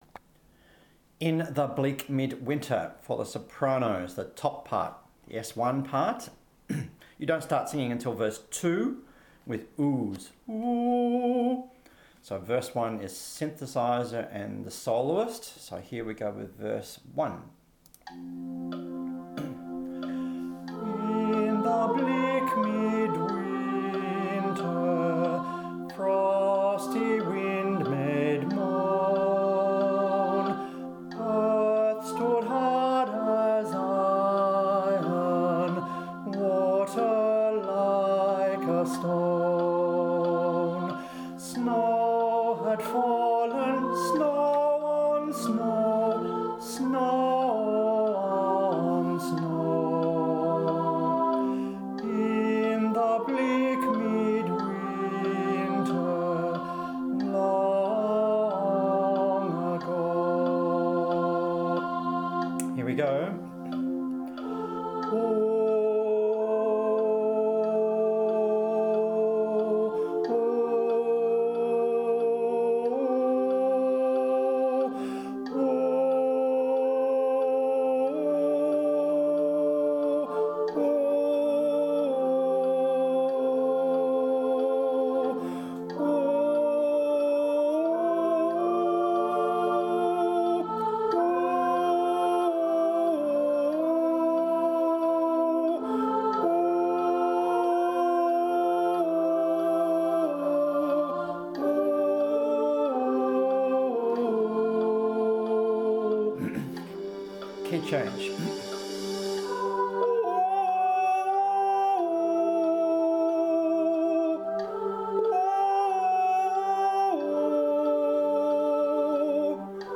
09-In-the-Bleak-Sop-1.mp3